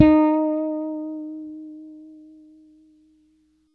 -MM BRYF D#5.wav